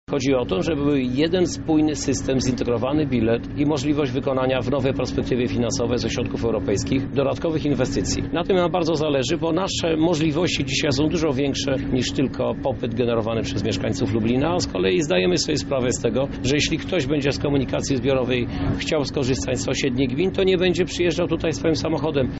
Chcemy rozbudowywać transport w obszarze podmiejskim – mówi Krzysztof Żuk, prezydent Lublina.